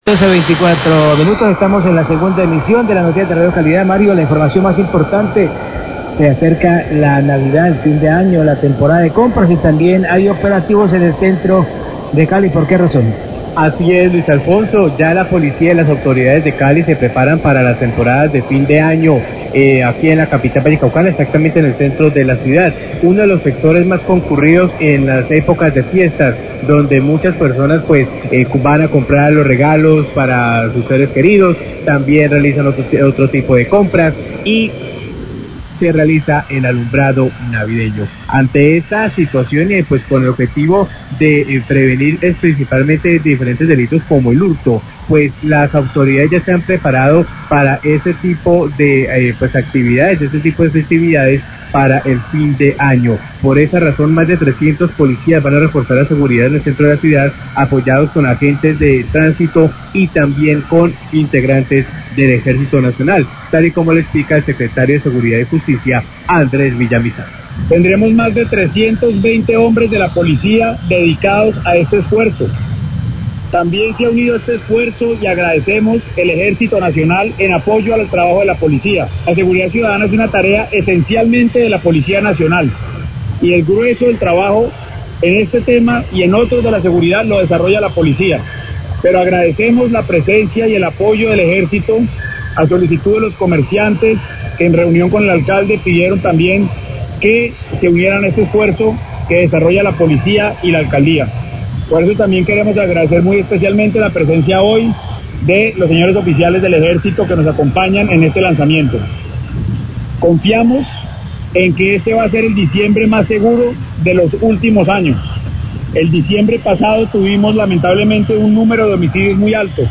Radio
NOTICIAS DE CALIDAD